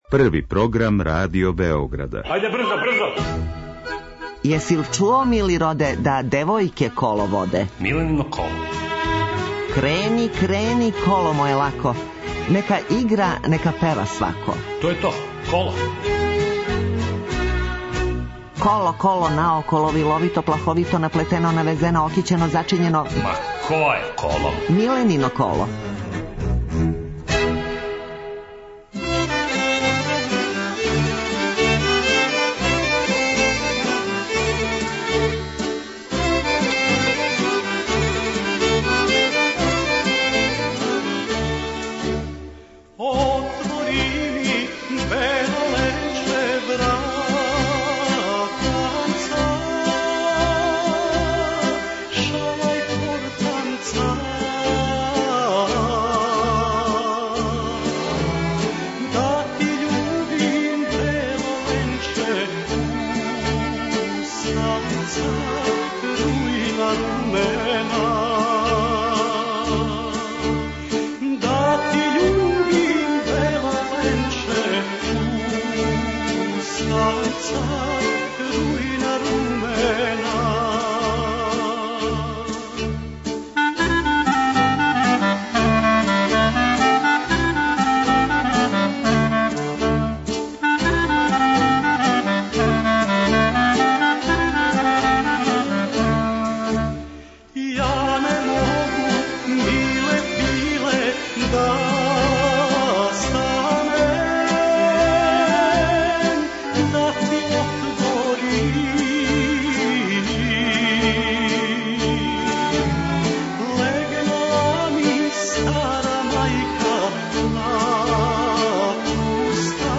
Емисија се емитује недељом од 11.05 до 12.00 о народној музици, искључиво са гостима који су на било који начин везани за народну музику, било изворну, било новокомпоновану (певачи, композитори, текстописци, музичари...). Разговор са гостом забавног карактера - анегдоте из професионалног живота, најдража песма, највећи успех, хоби и томе слично.